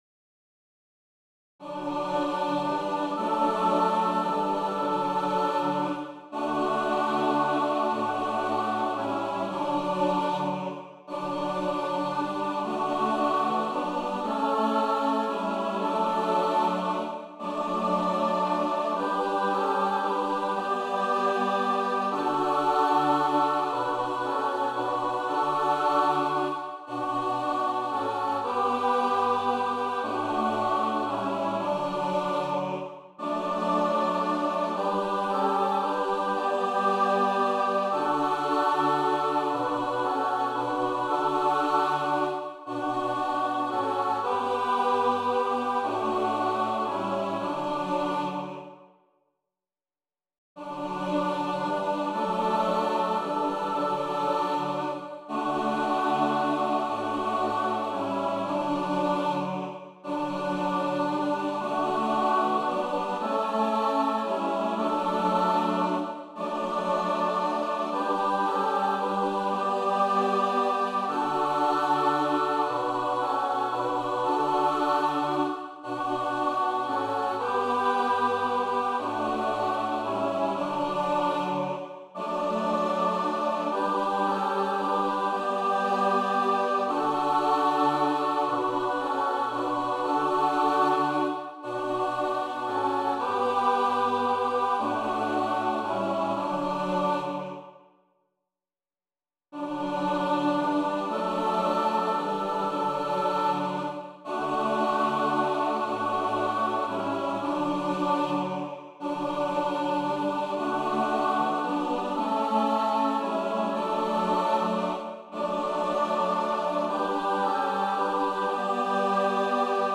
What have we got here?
SATB Voicing/Instrumentation: SATB